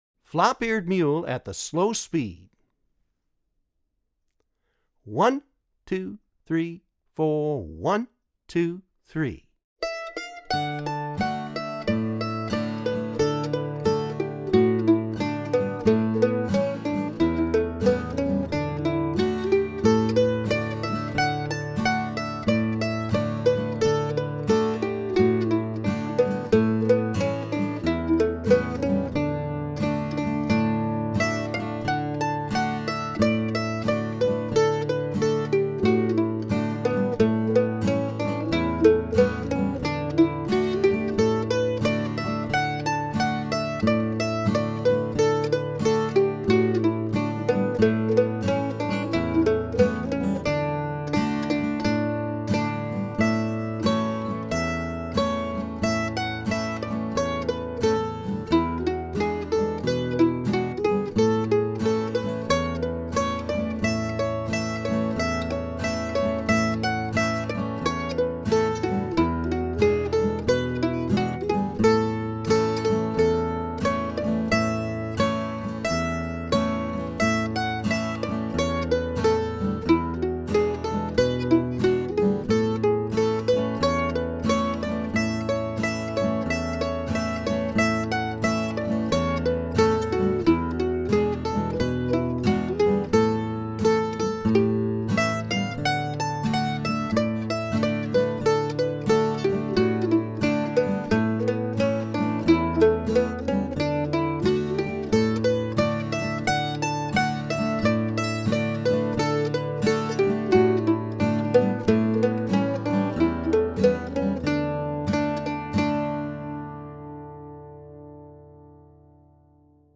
DIGITAL SHEET MUSIC - MANDOLIN SOLO
Traditional Mandolin Solo
(both slow and regular speed)